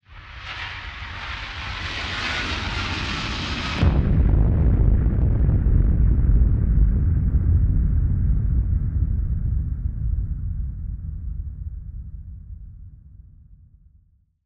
BF_DrumBombDrop-04.wav